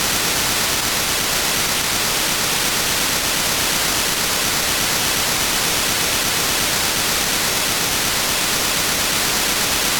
Slow Associated Control Channel for P25 Phase 2